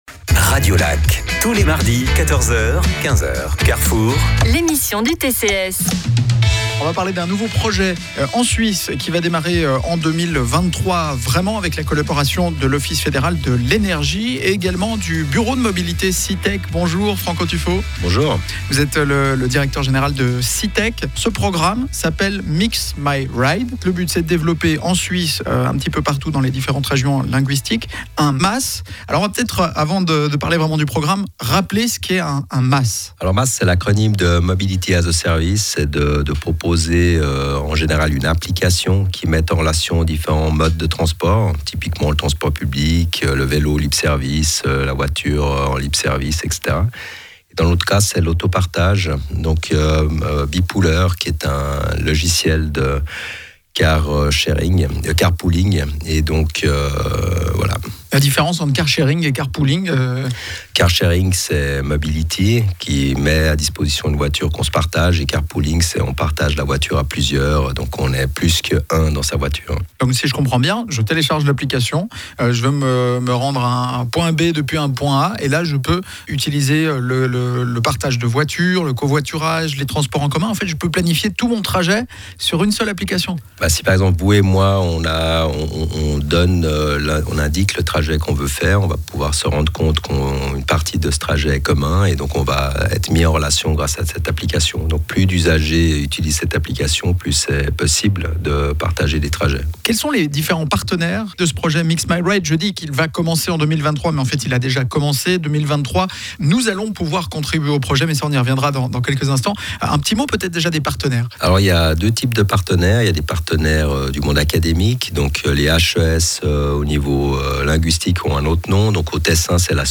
PODCAST – Radio Lac interroge Citec sur MixMyRide, l’application qui facilite la mobilité à Genève
Diffusé le 06 décembre 2022 sur Rhône FM
Radiolac-Interview-TU-MixMyRide-covoiturage-Geneve-integral.mp3